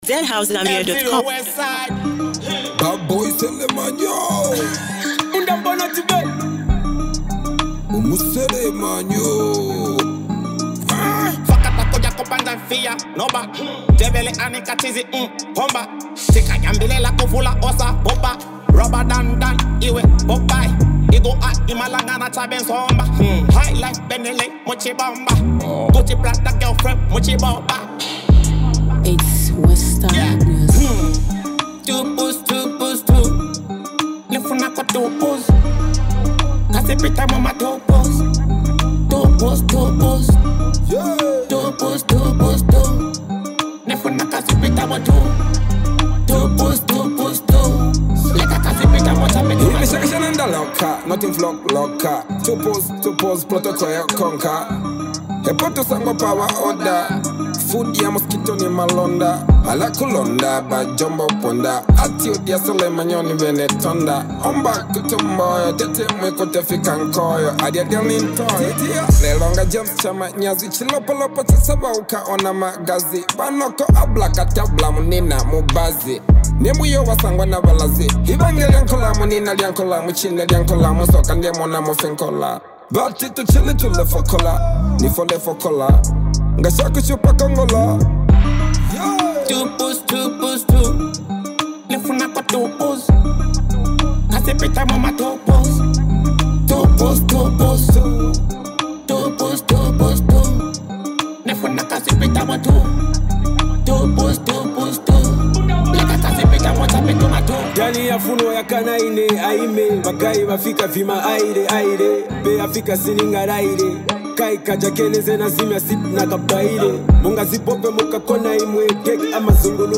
Experience the electrifying beats and vibrant energy